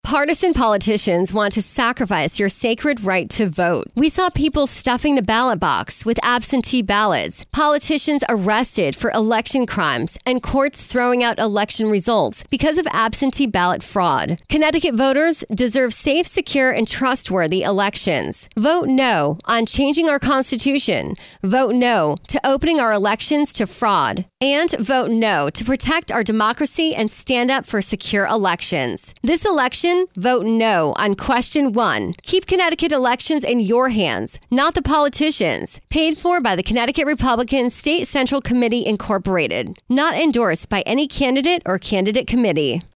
Vote No Radio Ad
Vote-No-Radio-Spot.mp3